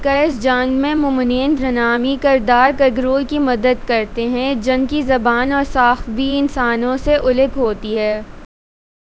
deepfake_detection_dataset_urdu / Spoofed_TTS /Speaker_10 /104.wav